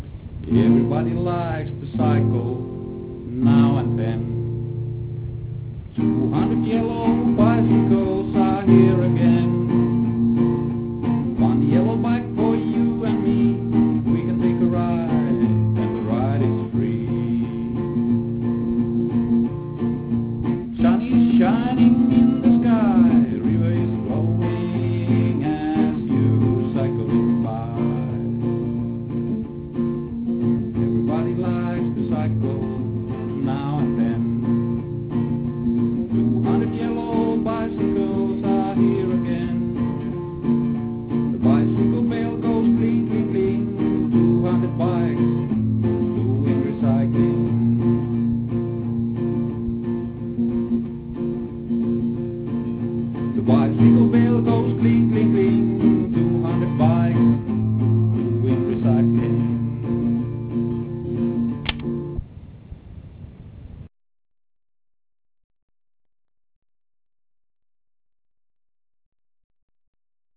using an old tape recorder and an out of tune guitar